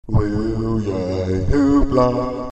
there's only 3 notes and it cuts off before the full 2 seconds has elapsed, it happens when i'm encoding them during streambox it cuts out the last half a second or so.